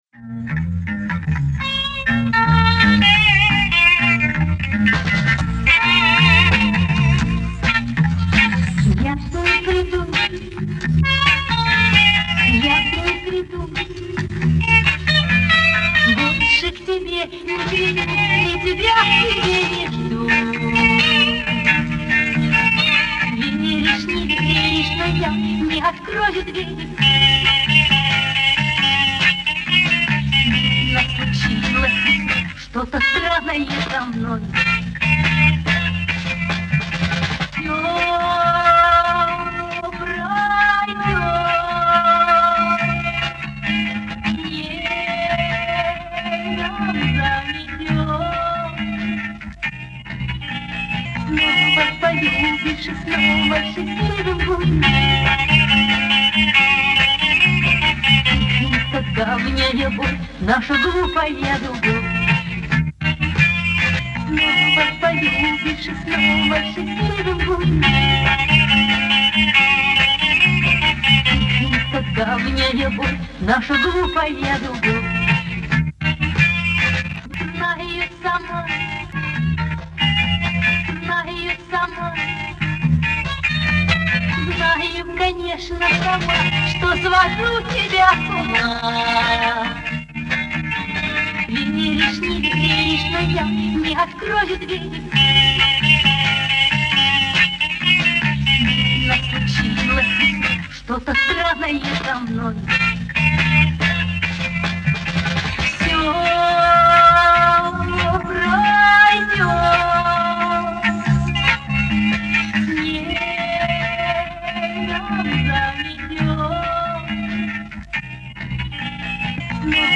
на ЦТ - в  другой аранжировке ок.1970г.